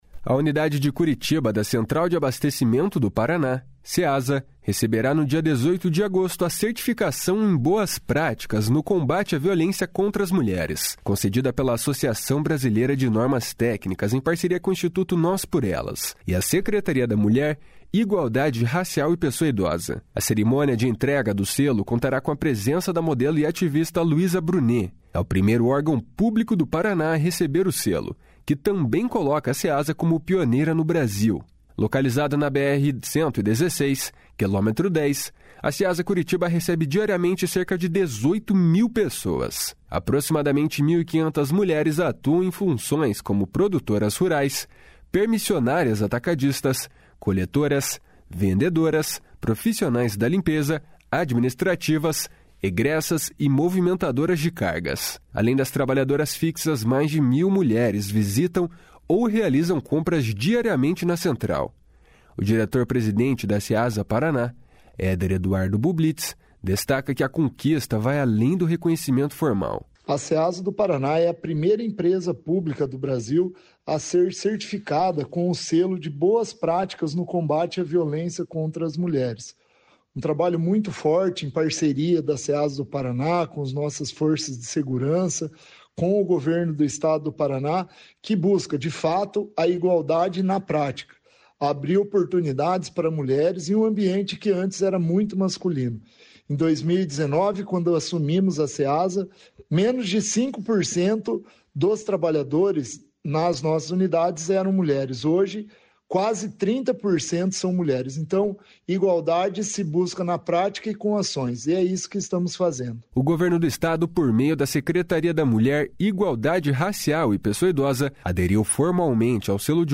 O diretor-presidente da Ceasa Paraná, Éder Eduardo Bublitz, destaca que a conquista vai além do reconhecimento formal. // SONORA EDUARDO BUBLITZ //